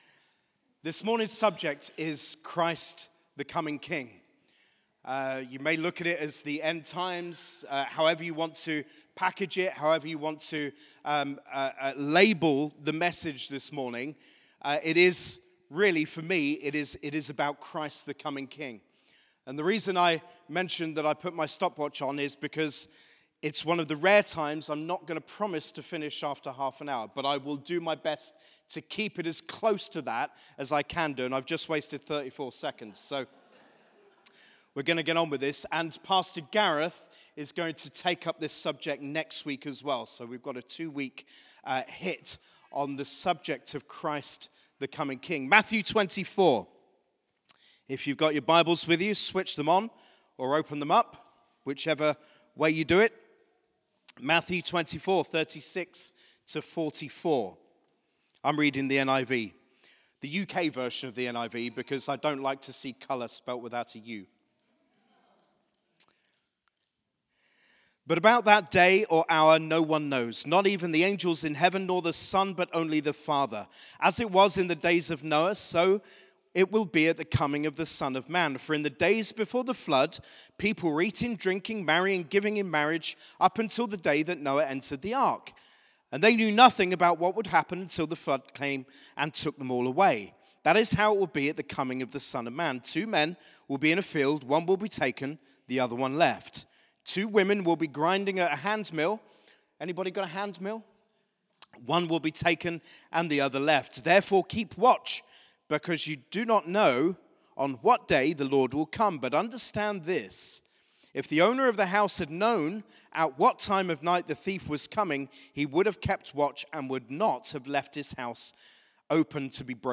Sermon - Christ the Coming King: Matthew 24:36-44